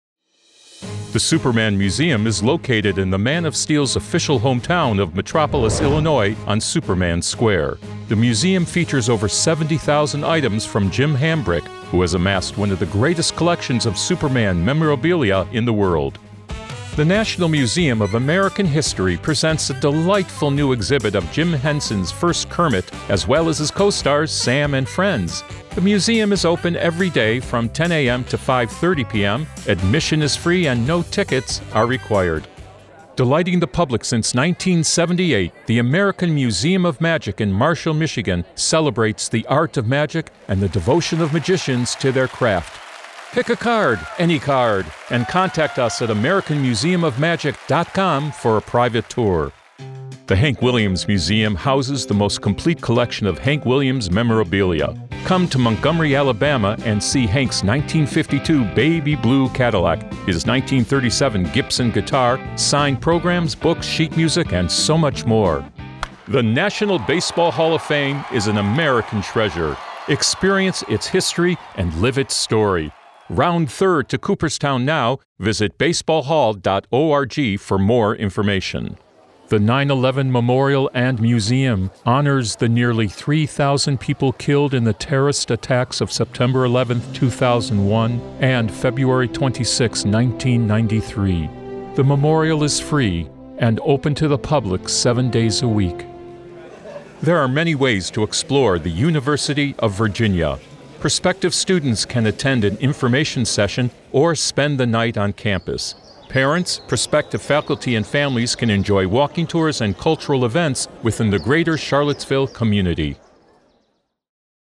English (American)
Commercial, Deep, Senior, Mature, Friendly
Corporate